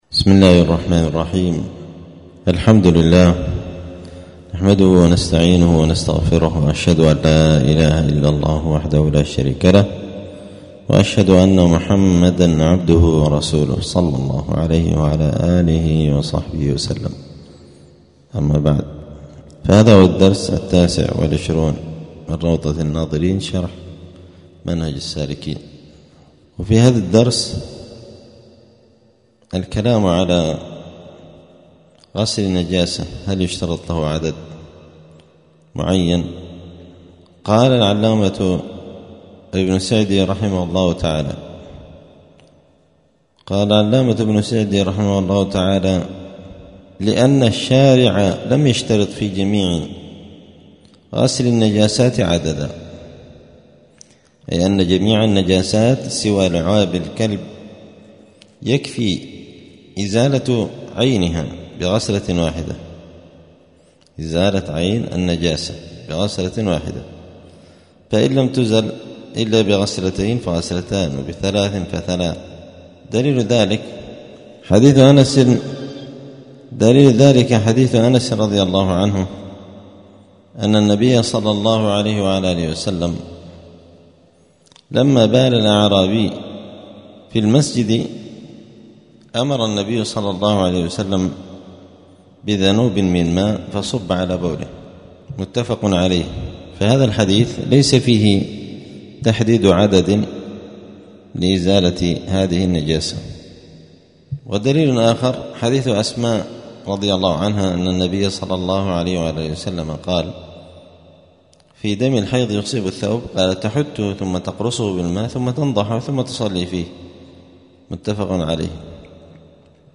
*الدرس التاسع والعشرون (29) {كتاب الطهارة باب الاستنجاء وآداب قضاء الحاجة هل يشترط عدد معين في غسل الجنابة؟}*
دار الحديث السلفية بمسجد الفرقان قشن المهرة اليمن